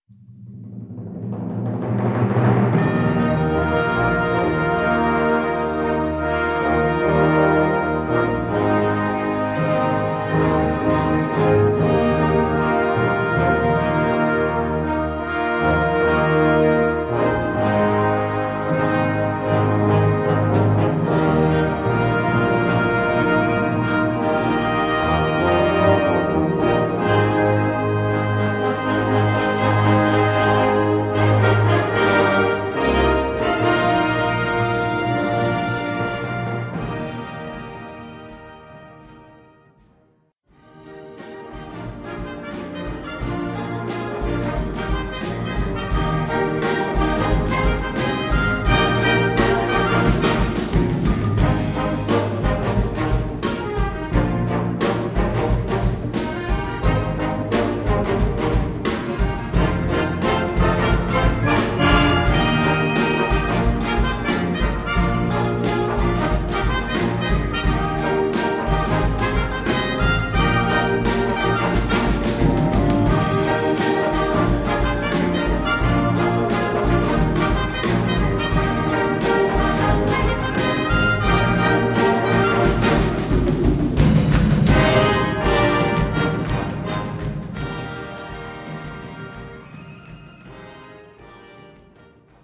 Gattung: Fanfare
Besetzung: Blasorchester